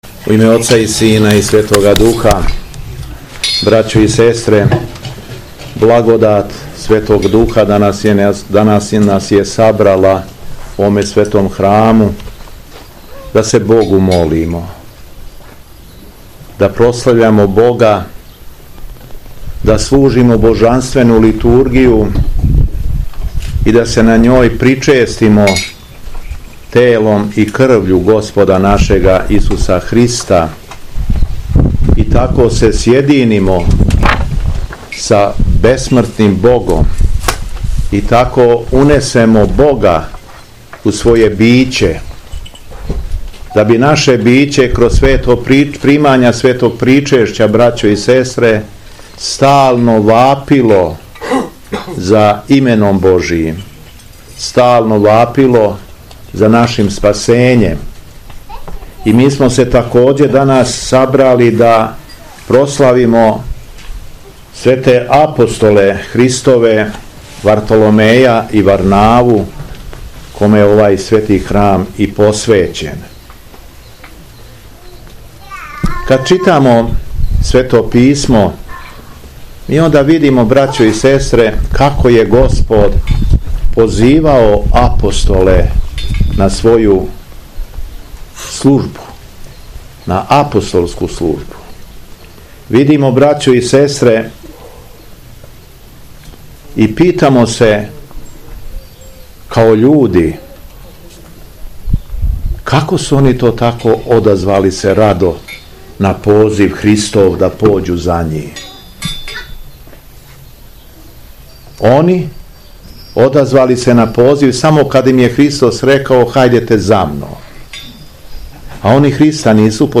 Беседа Његовог Високопреосвештенства Митрополита шумадијског г. Јована
Високопреосвећени Владика се обратио верном народу Богонадахнутом беседом речима: